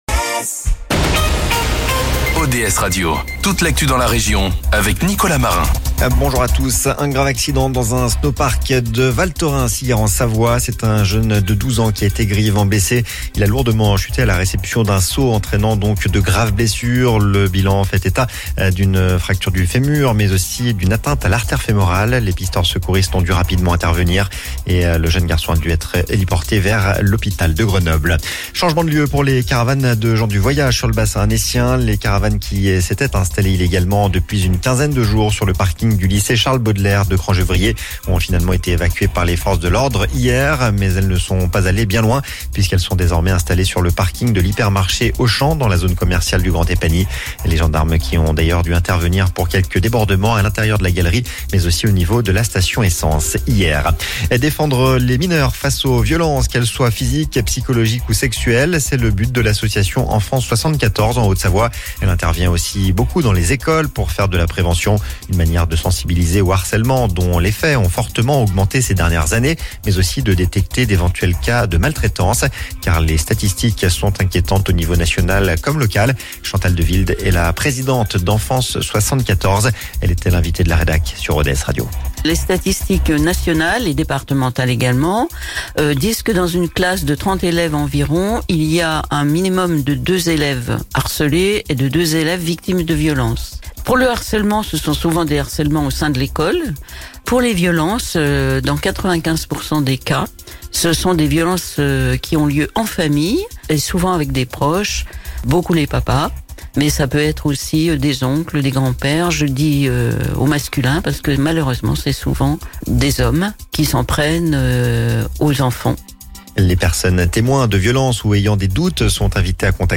Le Flash Info, le journal d'ODS radio